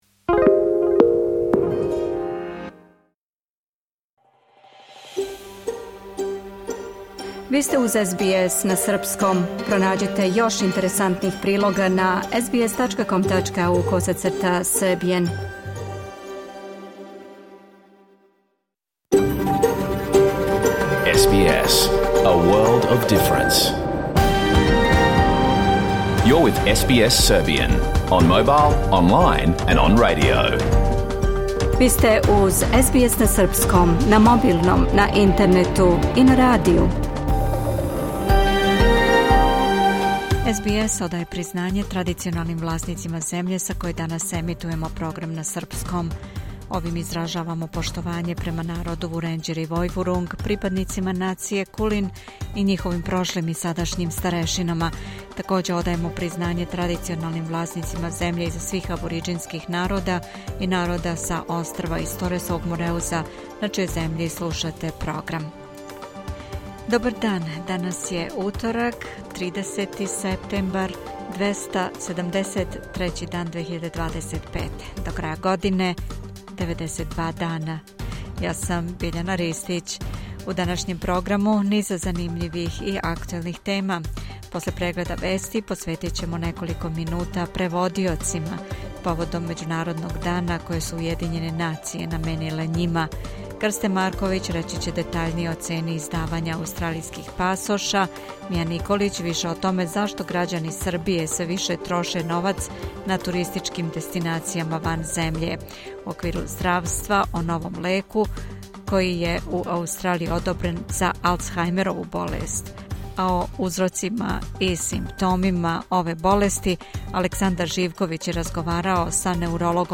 Програм емитован уживо 30. септембра 2025. године